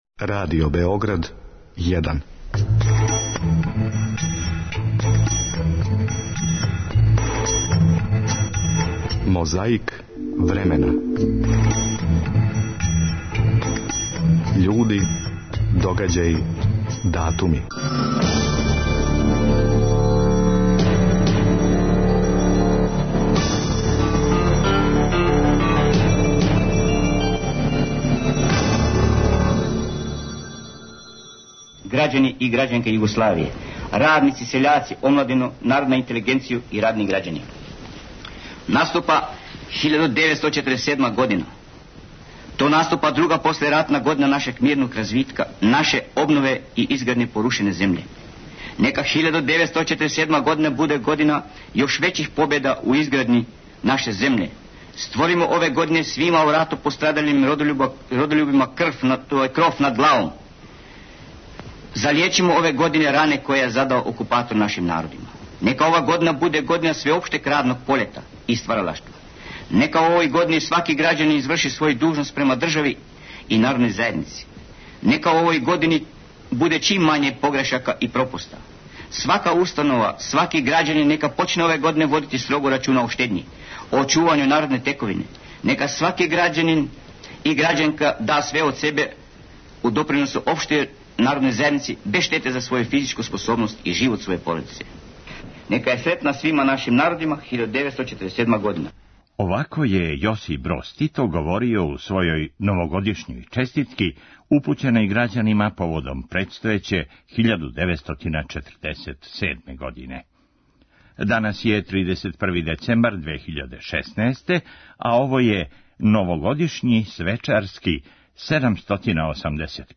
У новогодишњем Мозаику времена чућете одломак радио драме или боље радио комедије "Деда и ја". У улози ЈА била је Ружица Сокић.